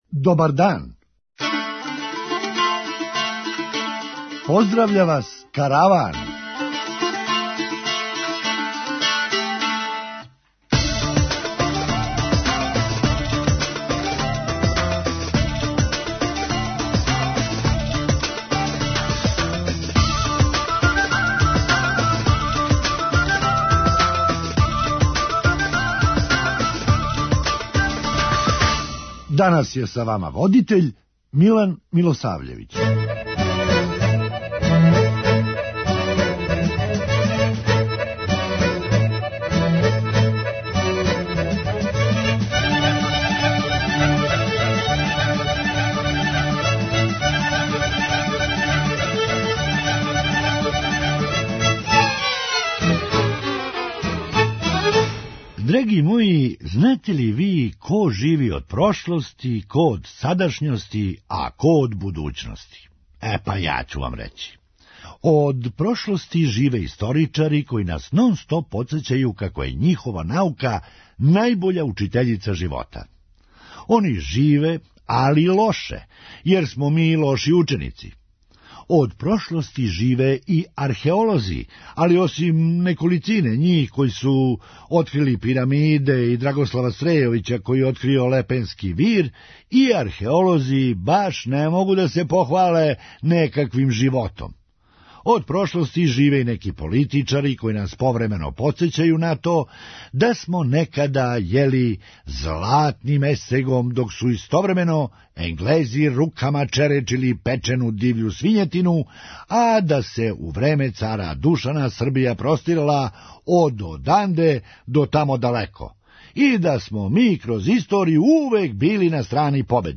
Хумористичка емисија
Па после кажите како су све последице закључавања због короне лоше. преузми : 9.01 MB Караван Autor: Забавна редакција Радио Бeограда 1 Караван се креће ка својој дестинацији већ више од 50 година, увек добро натоварен актуелним хумором и изворним народним песмама.